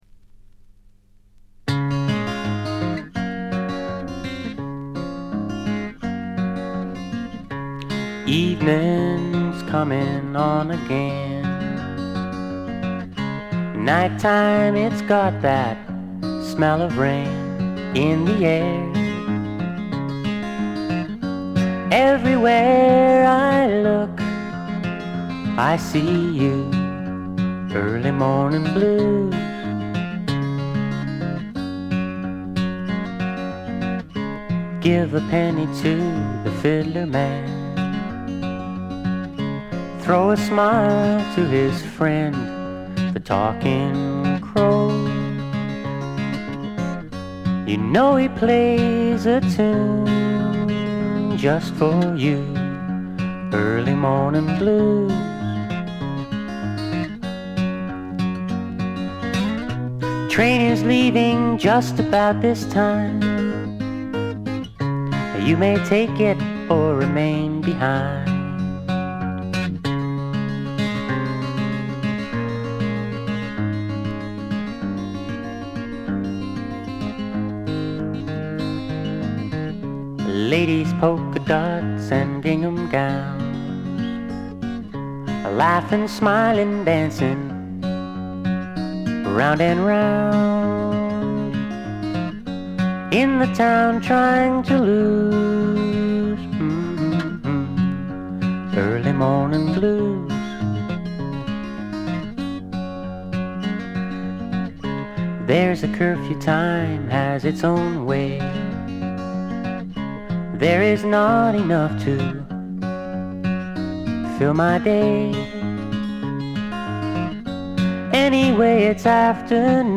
ドラムレスで、Heron的な木漏れ日フォークのほんわか感と、米国製メロー・フォーク的なまろやかさが同居した名作です。
試聴曲は現品からの取り込み音源です。